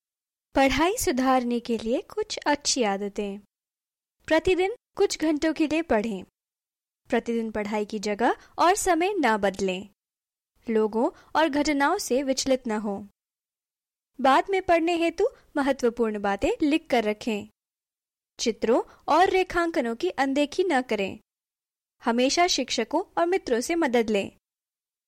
Female Professional Bilingual Voice Over Artist ( English/ Hindi )
Sprechprobe: Industrie (Muttersprache):